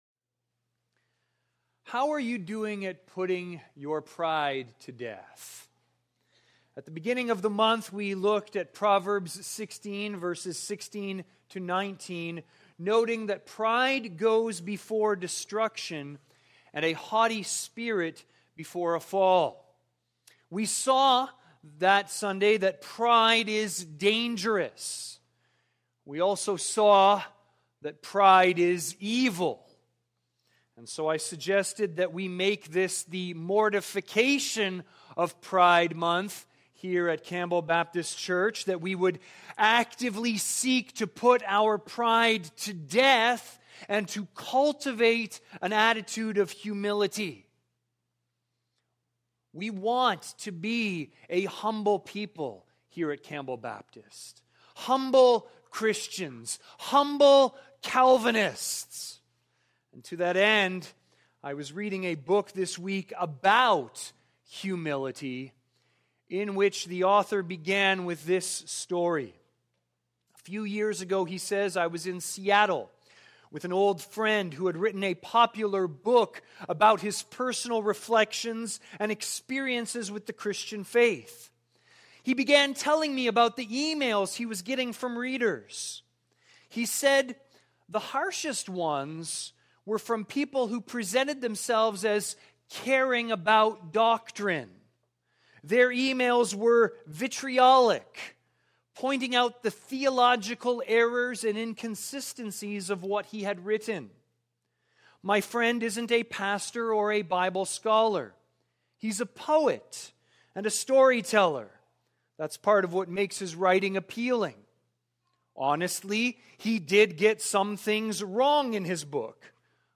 Sermons | Campbell Baptist Church
View the Sunday service. cbcwindsor · 2023-06-25 Sunday Service